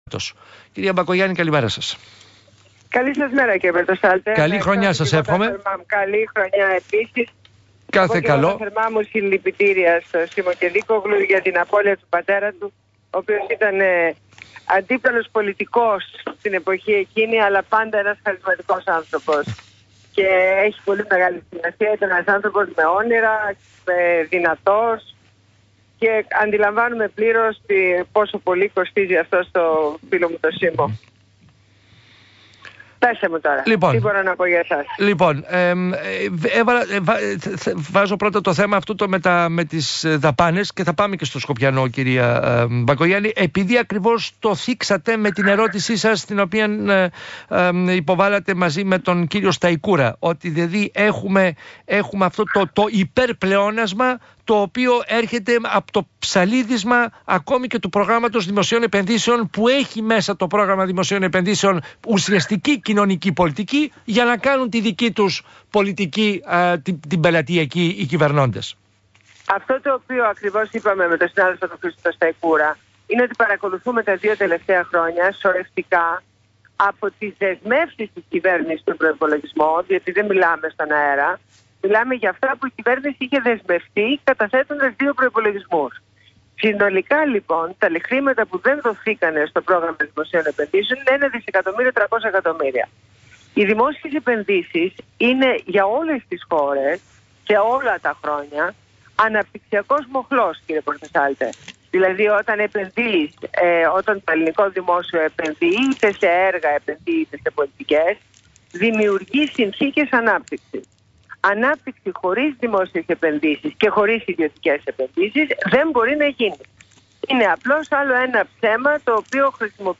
Συνέντευξη στο ραδιόφωνο του ΣΚΑΪ